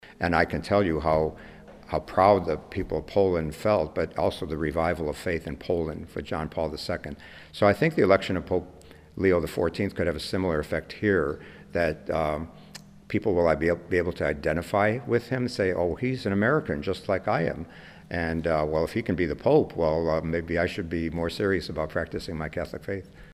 Bishop Thomas John Paprocki speaks in praise of Pope Leo at press conference held early on Friday, May 9, 2025